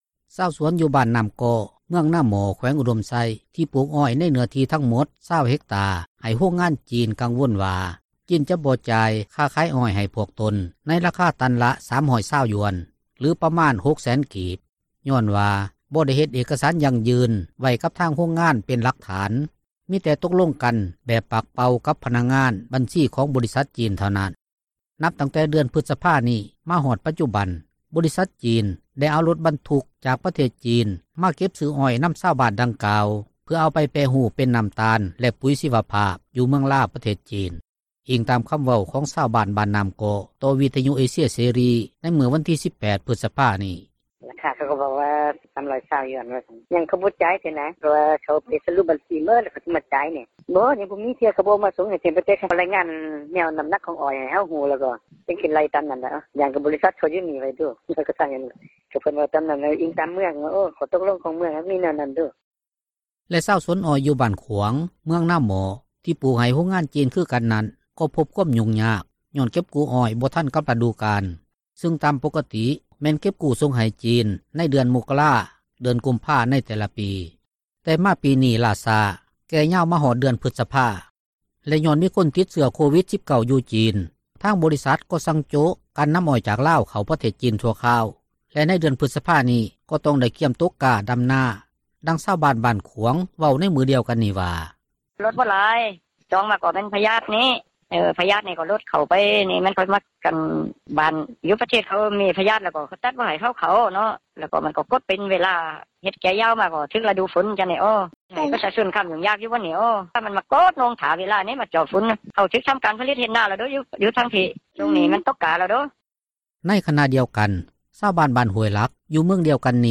ດັ່ງຊາວບ້ານບ້ານຂວາງ ເວົ້າໃນມື້ດຽວກັນນີ້ວ່າ:
ດັ່ງຊາວບ້ານຫ້ວຍເຫລັກ ເວົ້າຕໍ່ວິທຍຸເອເຊັຽເສຣີໃນມື້ວັນທີ 18 ພຶສພານີ້ວ່າ: